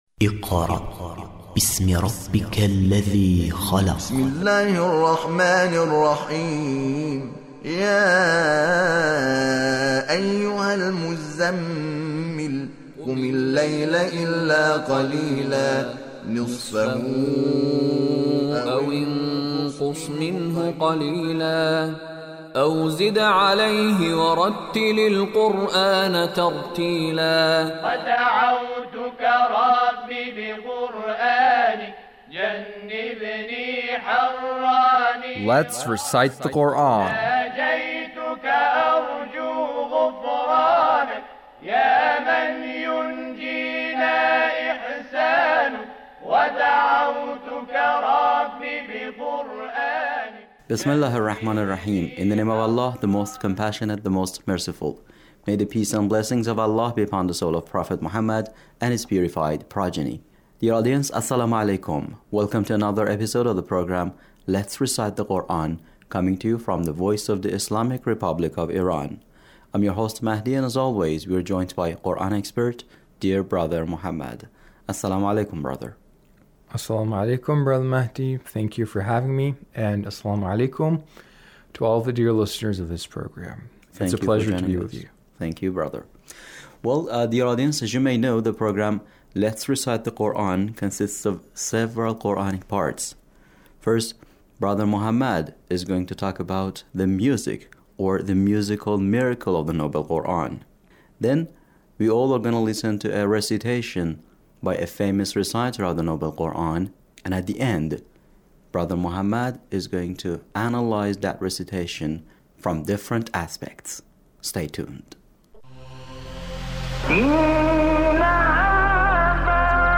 Abul Ainain Shuaisha recitation